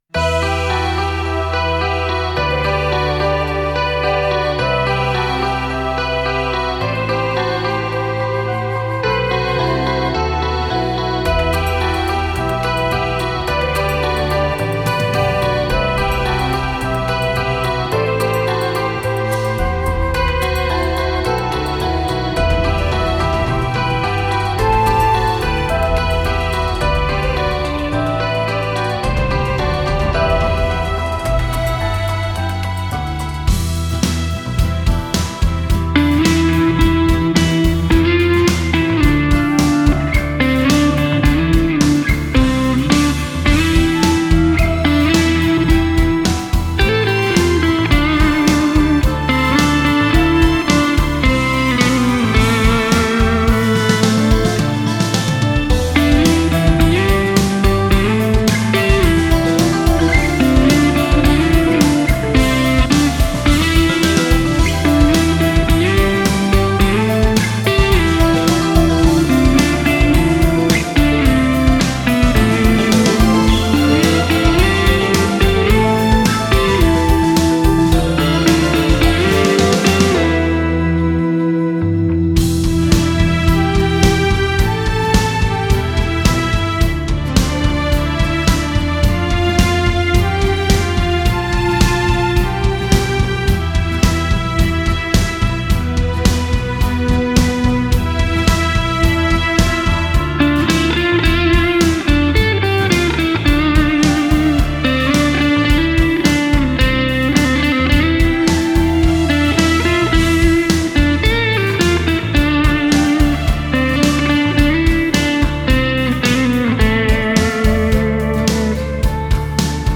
Ηλεκτρική Κιθάρα